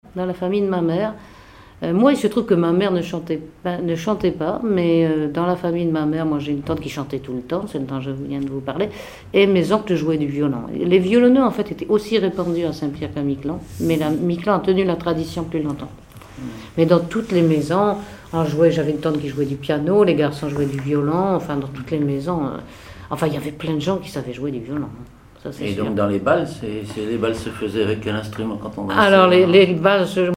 Conversation sur les musiciens de Saint-Pierre et Miquelon
Catégorie Témoignage